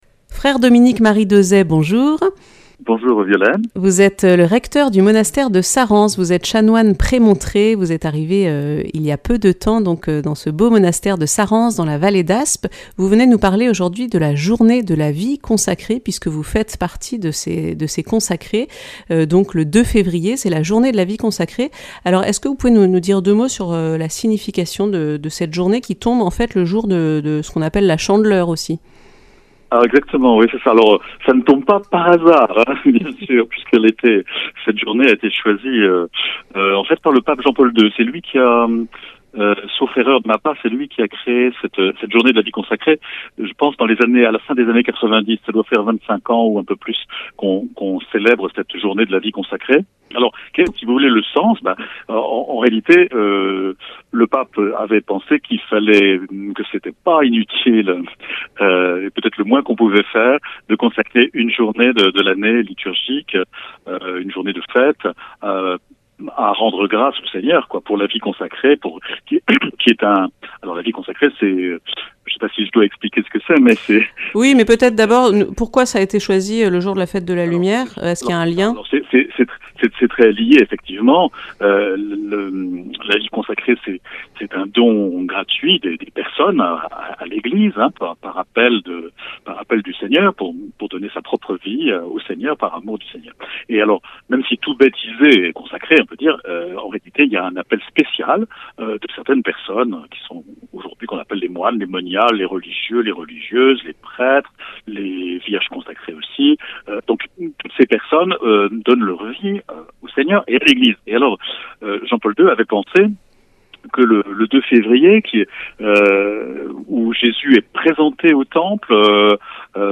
Accueil \ Emissions \ Infos \ Interviews et reportages \ La vie consacrée, qu’est-ce que c’est ?